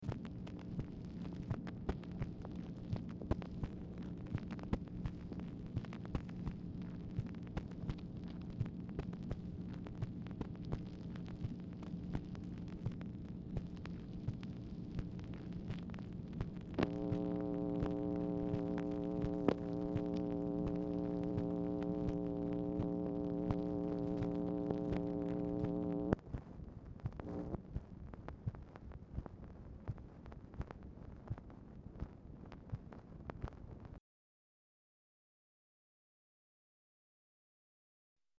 Telephone conversation # 4813, sound recording, MACHINE NOISE, 8/7/1964, time unknown | Discover LBJ
Format Dictation belt